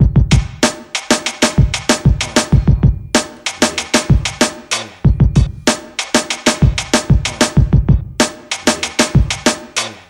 • 95 Bpm Rap Drum Groove A Key.wav
Free drum beat - kick tuned to the A note. Loudest frequency: 1718Hz
95-bpm-rap-drum-groove-a-key-uHg.wav